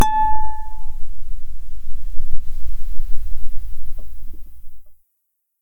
acoustic-guitar
A5_mf.mp3